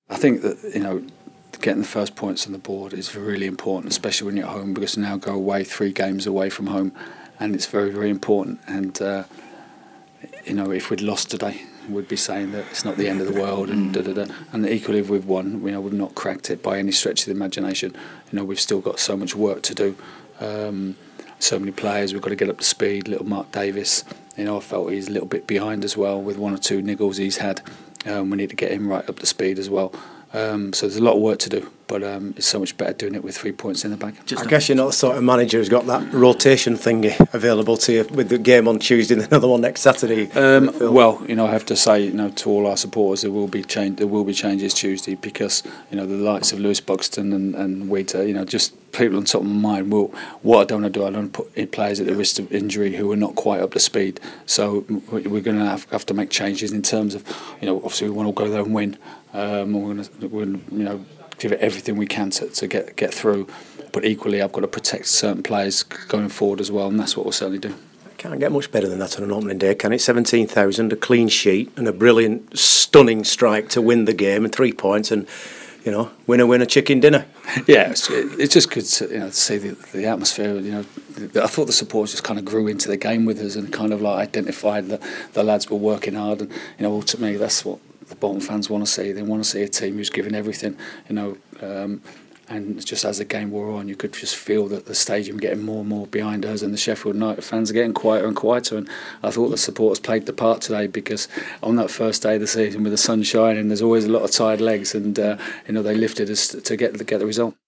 Phil Parkinson speaks to BBC Radio Manchester following his sides 1-0 opening day victory over Sheffield United.